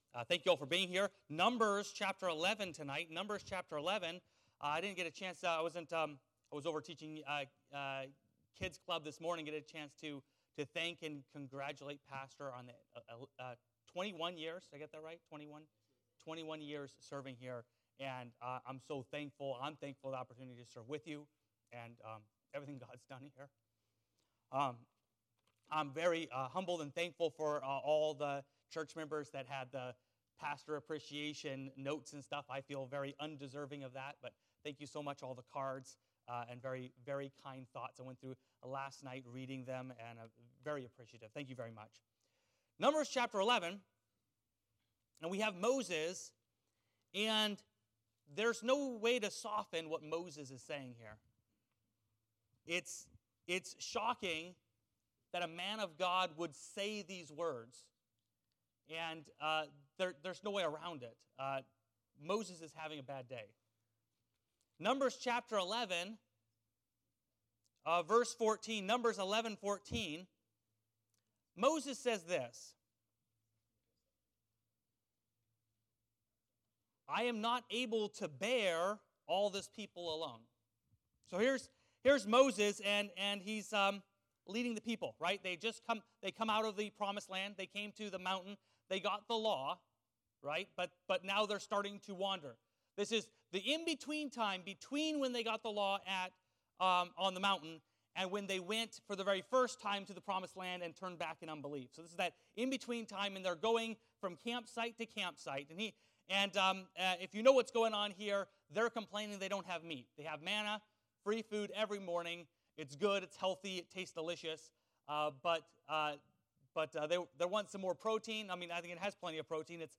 Numbers 11:19-17 Service Type: Sunday Evening « Important Questions Behaving Under Persecution